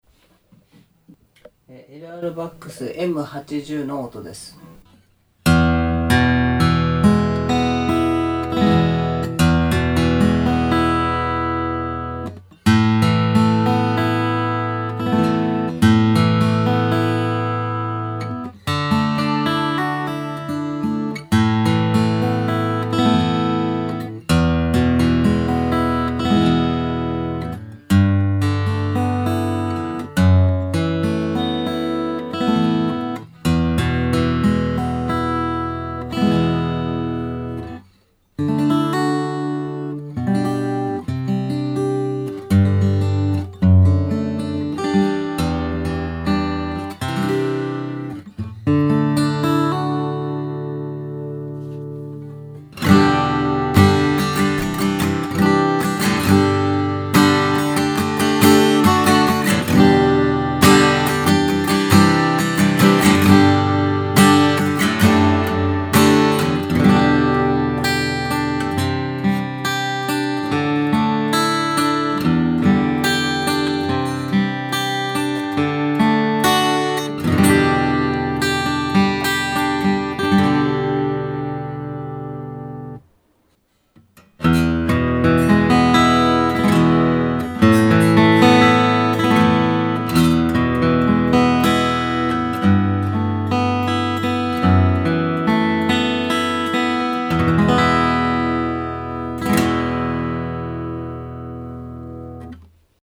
LR Baggs M80 という振動を拾えるマグネチックピックアップをテストいたしました。
叩き系には向きませんが，最も手軽にエレアコ化することは可能だと思います。